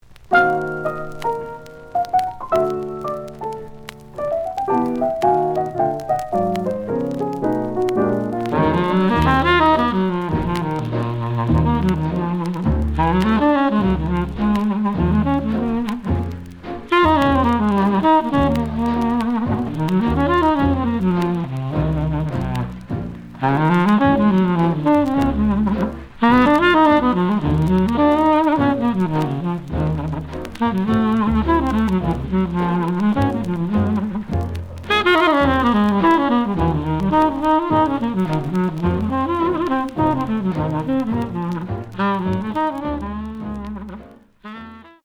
The audio sample is recorded from the actual item.
●Genre: Dixieland Jazz / Swing Jazz
Slight edge warp.